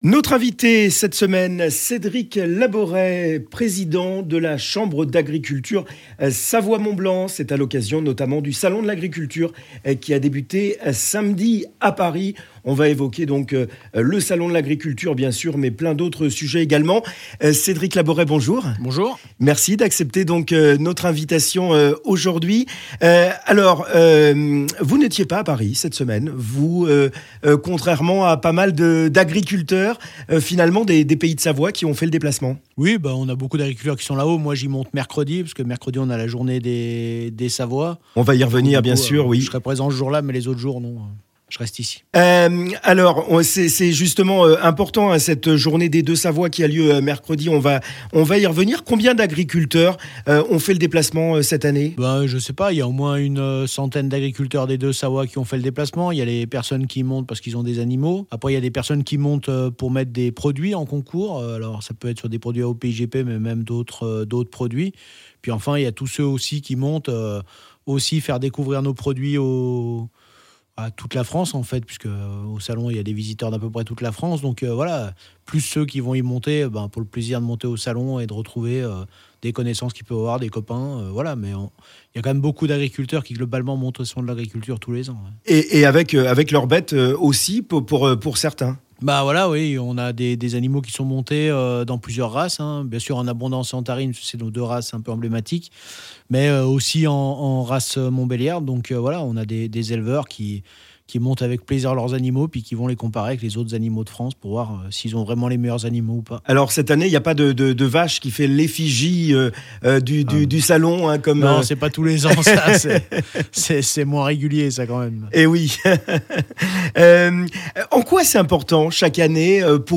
Il était l'Invité de la Rédac sur ODS Radio cette semaine : Télécharger le podcast Partager : Tags : savoie haute-savoie paris agriculteurs salon-de-l-agriculture